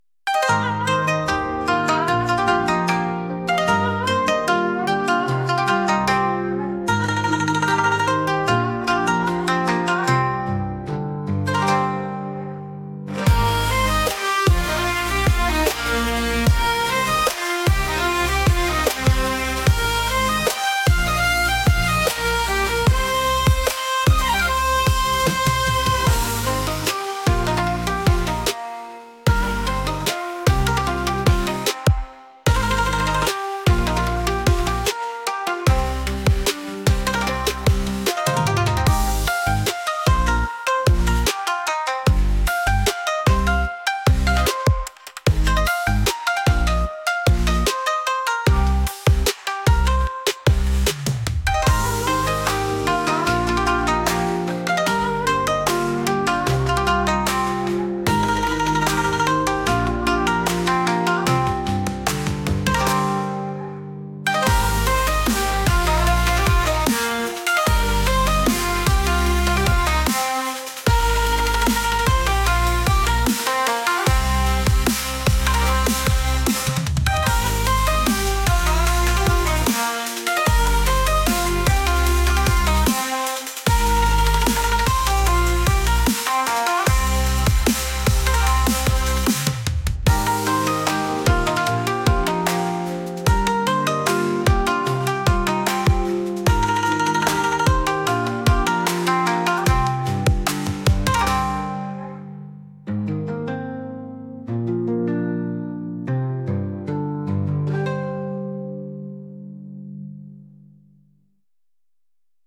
花見をしながらお酒を飲む和楽器を使った曲です。